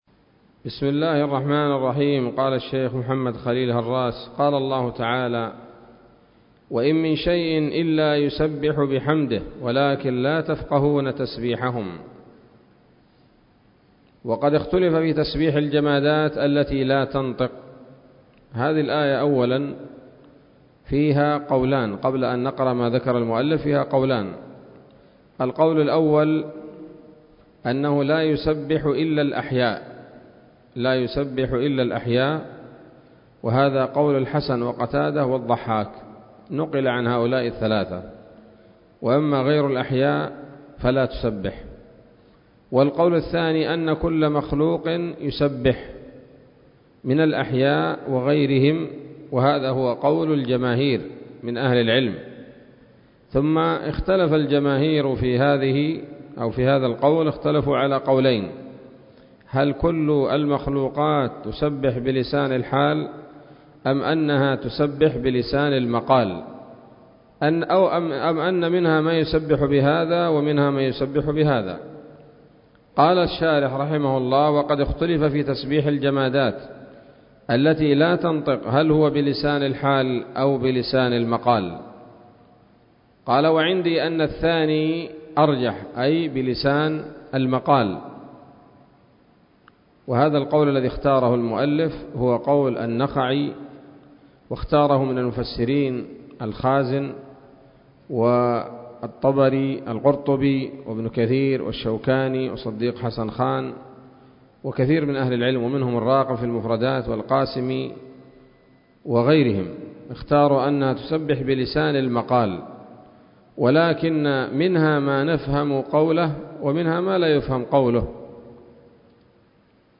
الدرس الثاني والسبعون من شرح العقيدة الواسطية للهراس